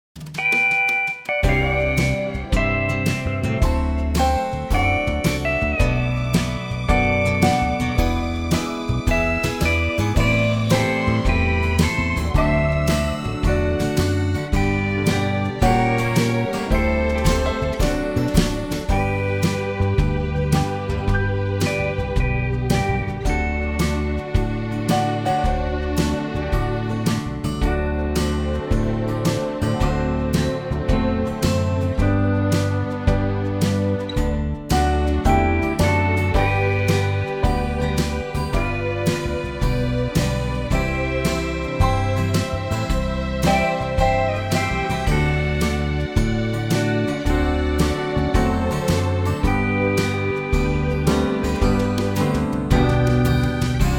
Unique Backing Tracks
key G
key - G - vocal range - F# to A
Country arrangement